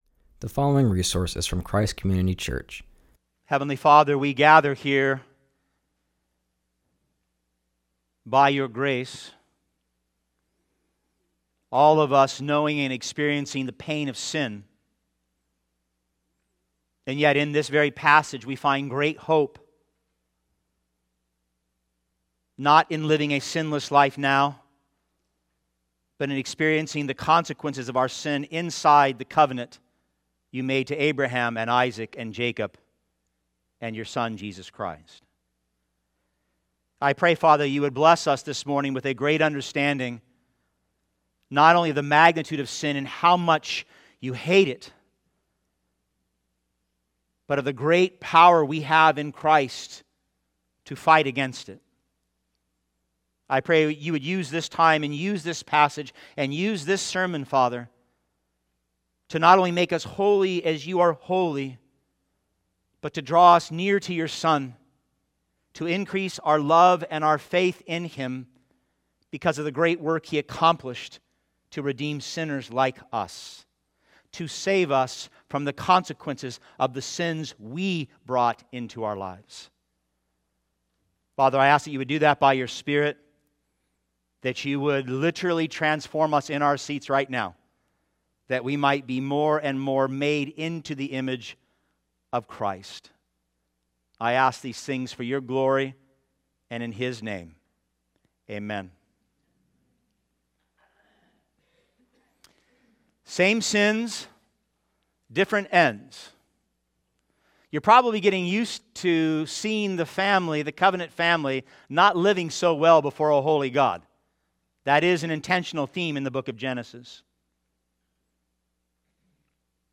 preaches from Genesis 27:41-28:9.